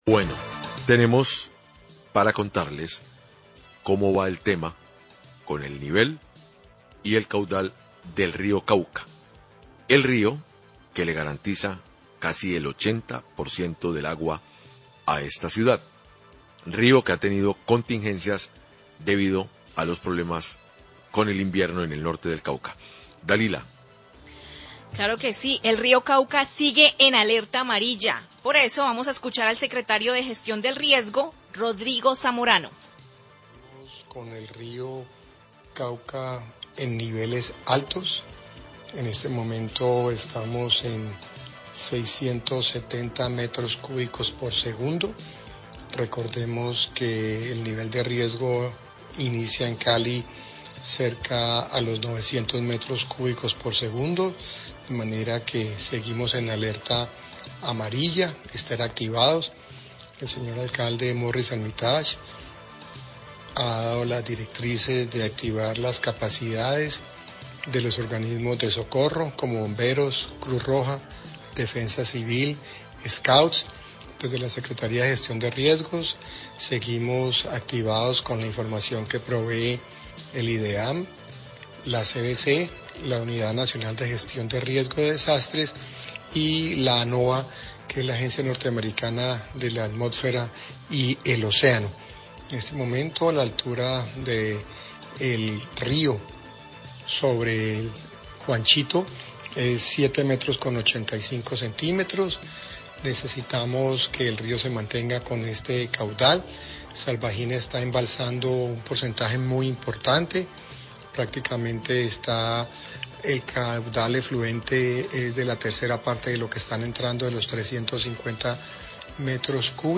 Radio
NOTICIAS DE CALIDAD
El secretario de Gestión de Riesgo, Rodrigo Zamorano, habló sobre el nivel del río Cauca y sobre la alerta amarilla que ha activado las capacidades de los organismos de socorro para atender cualquier emergencia.